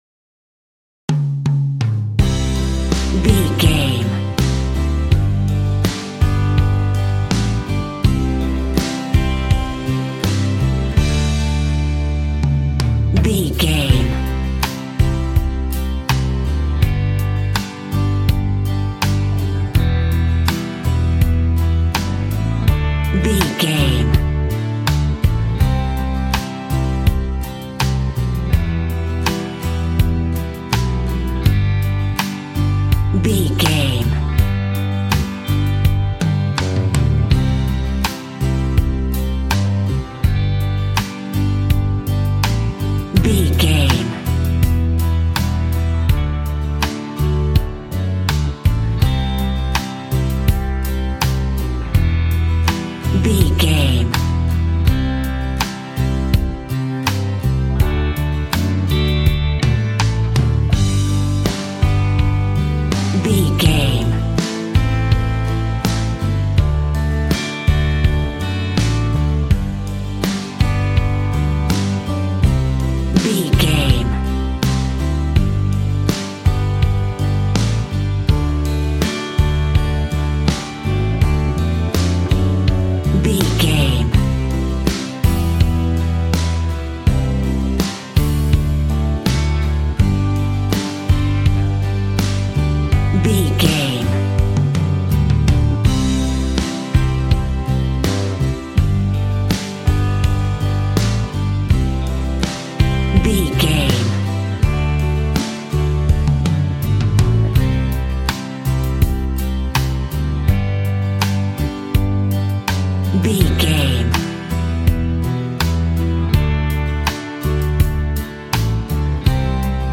Ionian/Major
romantic
sweet
happy
acoustic guitar
bass guitar
drums